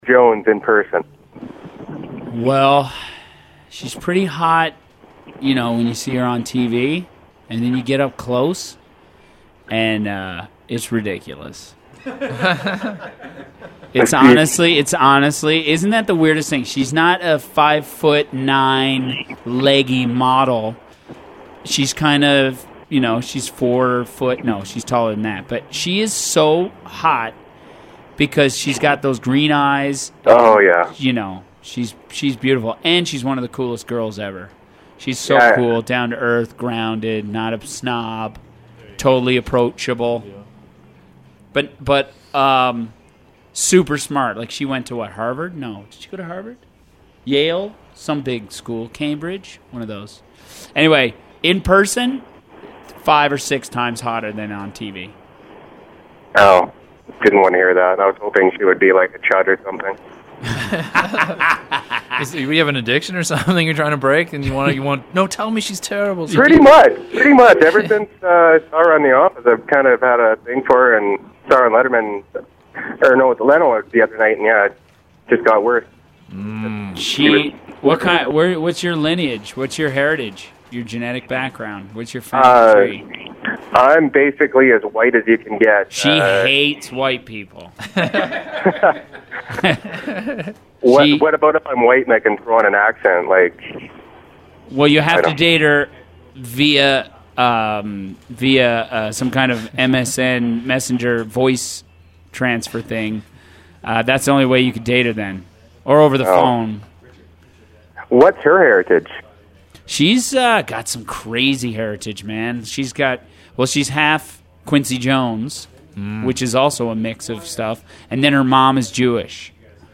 Here’s the conversation, plus a few calls we got that didn’t air on the show.
Call from a Newf